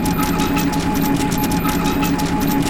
target.ogg